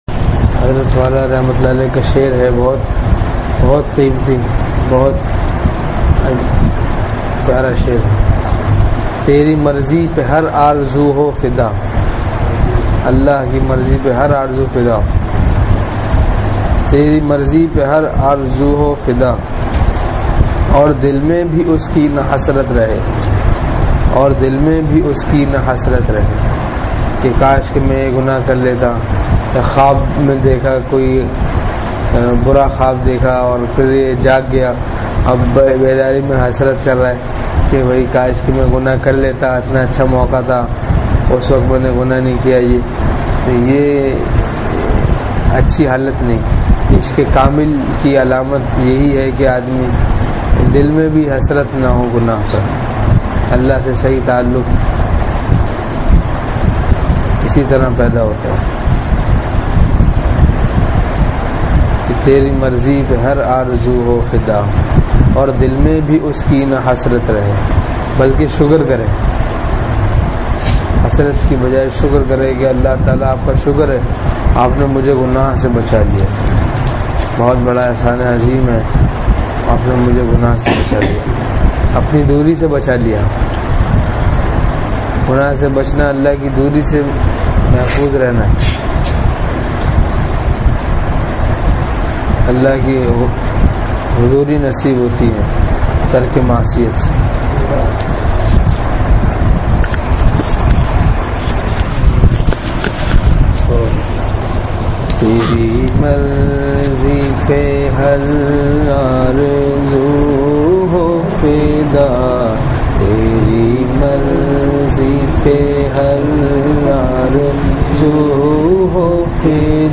مجلس بعد نمازِ عشاء